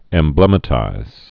(ĕm-blĕmə-tīz) also em·blem·ize (ĕmblə-mīz)